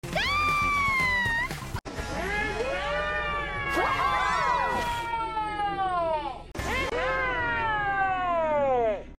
Female screaming sound, kids cheering sound effects free download
Female screaming sound, kids cheering sound, kids saying ahhh sound